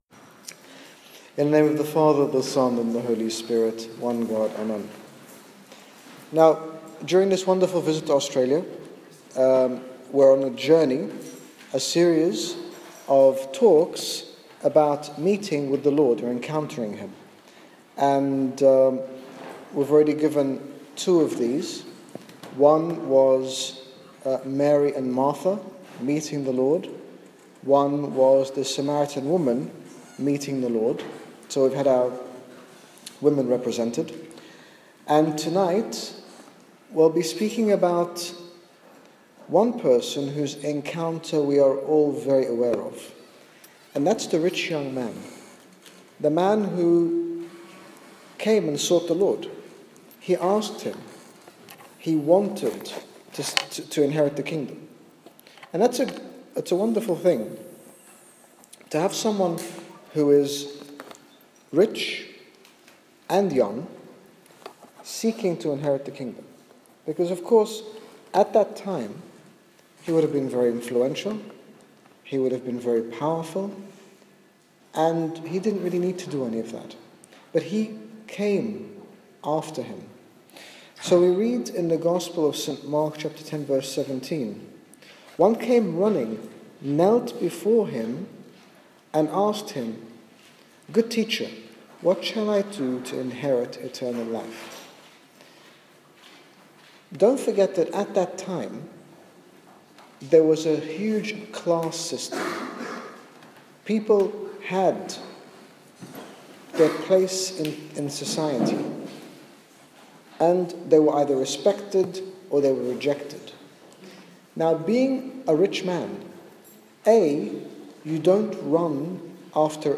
In this talk His Grace Bishop Angaelos uses the Biblical account of the Rich Young Man to illustrate how much we stand to gain if we take the leap of faith and commit our lives to God. His Grace talks about the various things that hold us back from a life with God, and emphasises the importance of a slow but gradual growth in spiritual life.